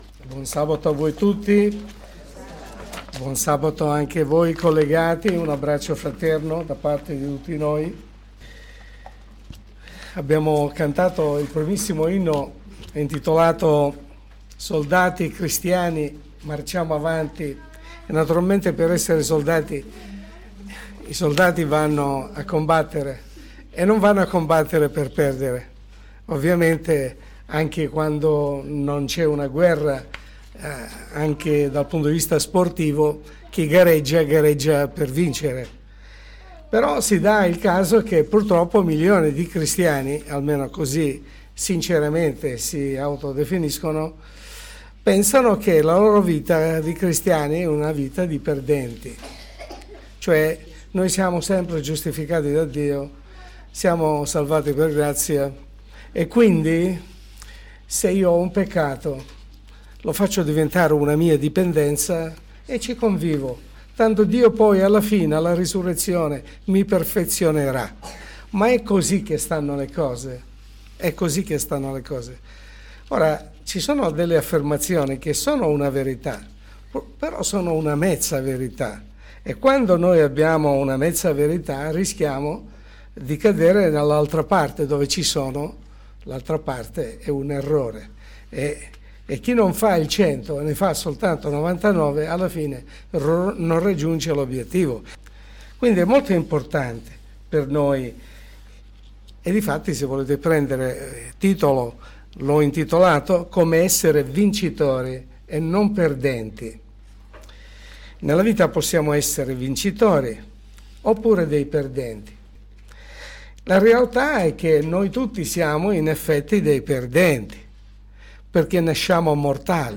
E’ proprio vero che Cristo ha vinto al posto nostro? Sermone pastorale